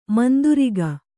♪ manduriga